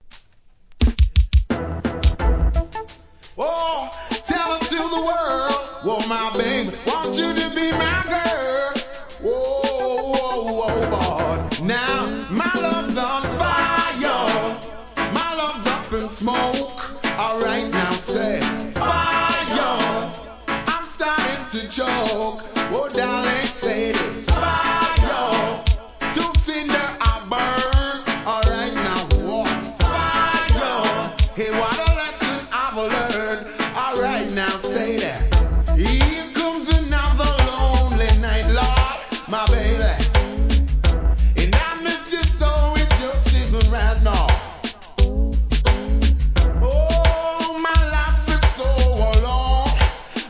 REGGAE
ベースの効いた軽快なDANCE HALL RHYTHMで歌い上げます。